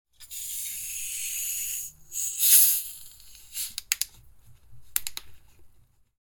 Misc / Sound Effects 4 Jan, 2026 Carbonated Bottle Opening Sound Effect Read more & Download...
Carbonated-bottle-opening-sound-effect.mp3